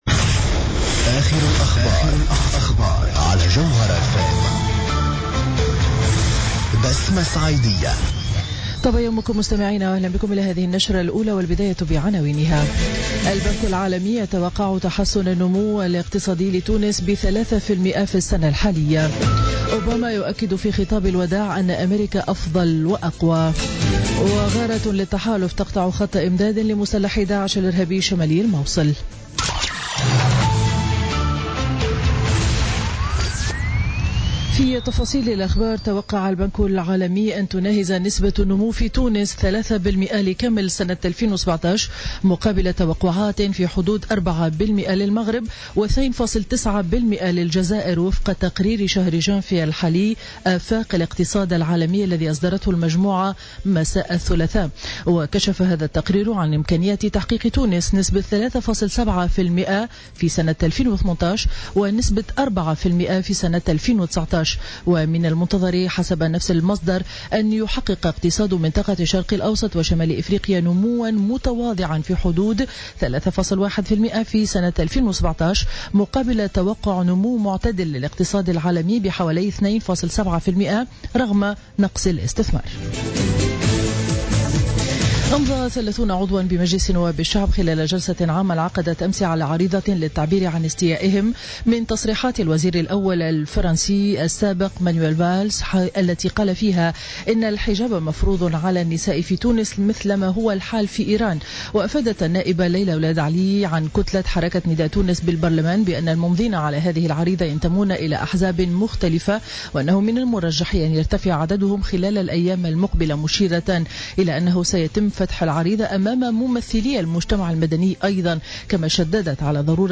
نشرة أخبار السابعة صباحا ليوم الأربعاء 11 جانفي 2017